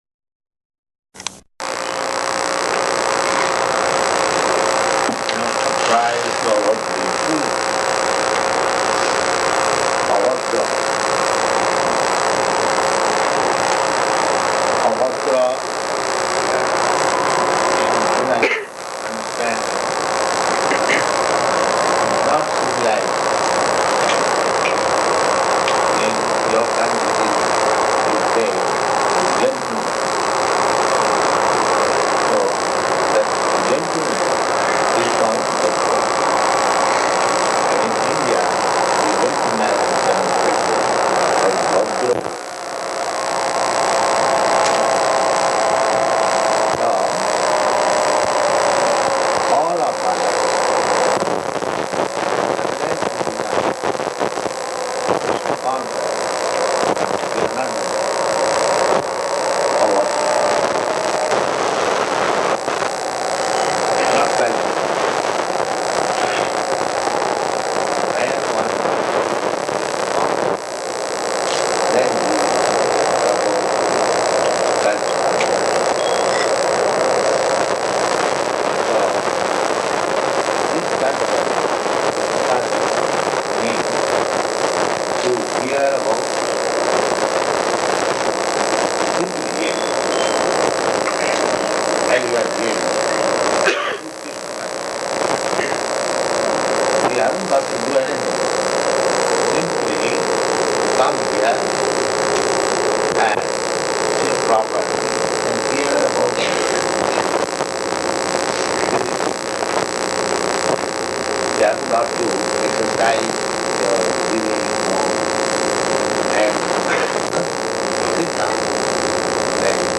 Śrīmad-Bhāgavatam 1.2.18 --:-- --:-- Type: Srimad-Bhagavatam Dated: July 19th 1974 Location: New Vrindaban Audio file: 740719SB.NV.mp3 [recorded on faulty equipment] Prabhupāda: Naṣṭa-prāyeṣv abhadreṣu [ SB 1.2.18 ].